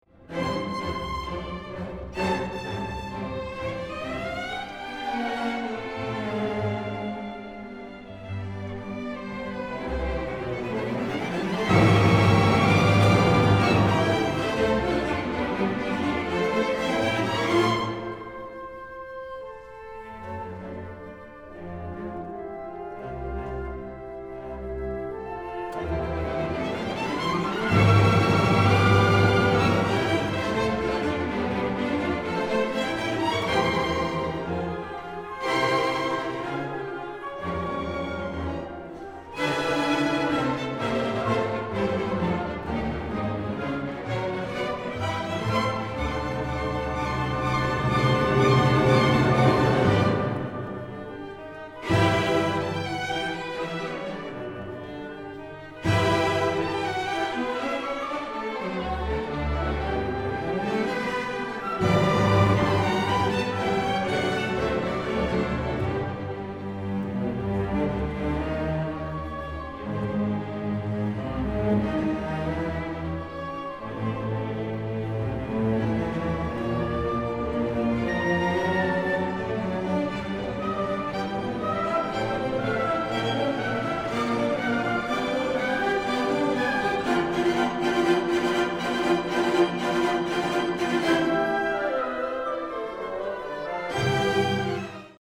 Northeastern University Symphony Orchestra
4/14/2018 Fenway Center